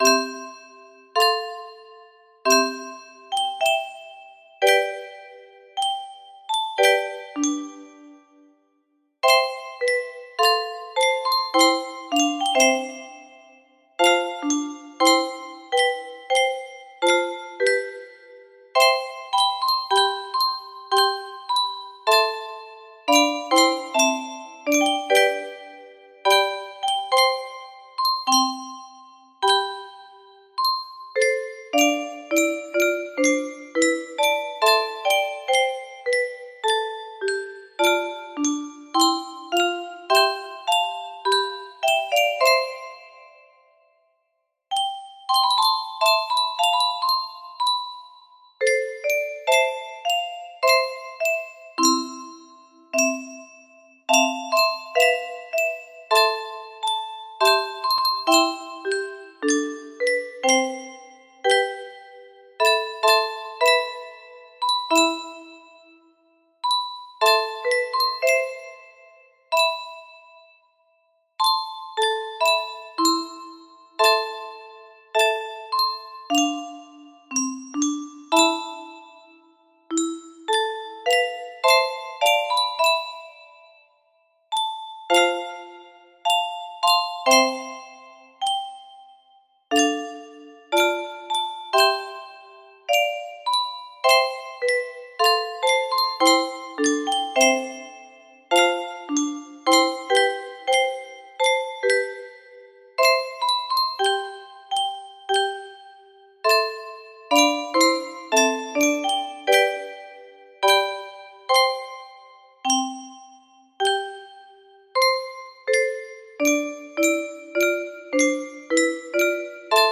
Traditional - Gebed van Paul Kruger music box melody